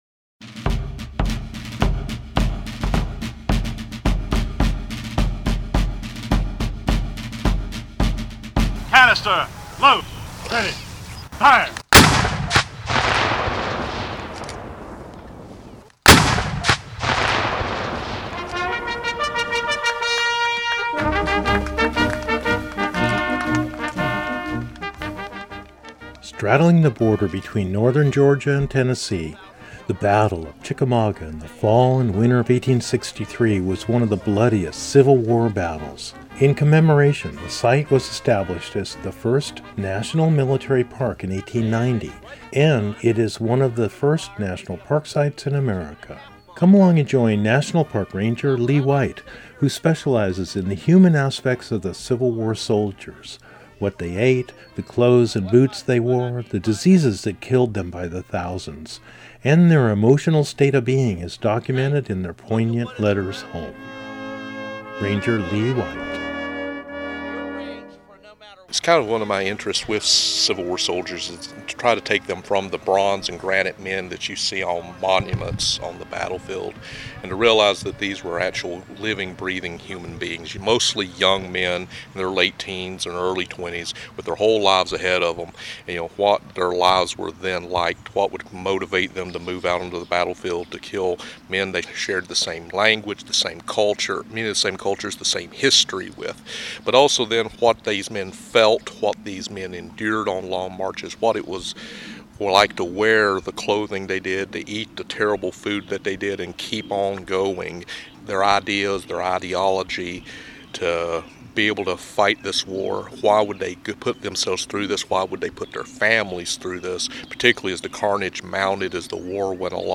recorded live on location across America and around the world